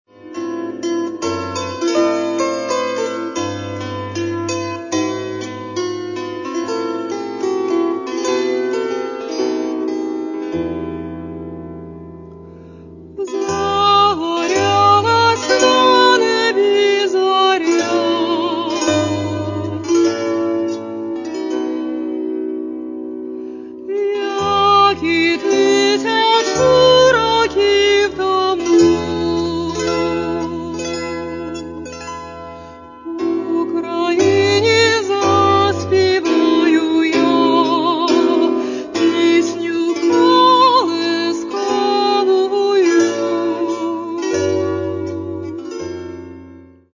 Каталог -> Народная -> Бандура, кобза